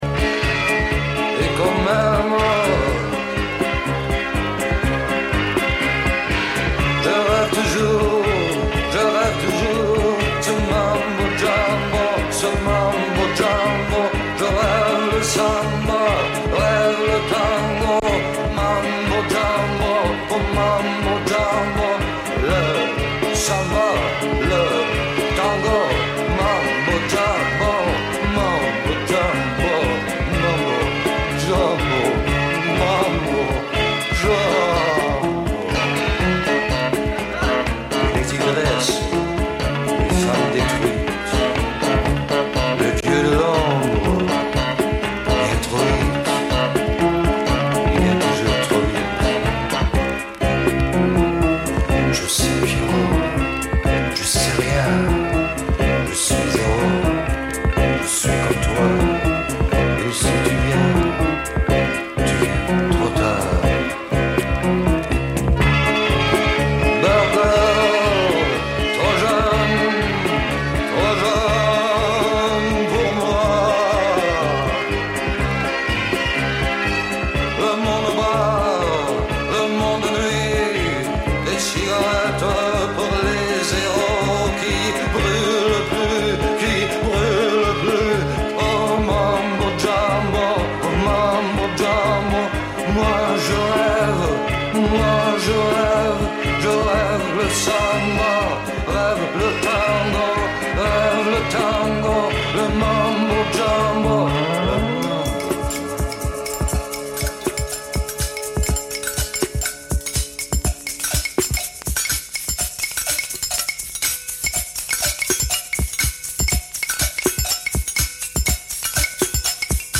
Musik vergangener Tage Dein Browser kann kein HTML5-Audio.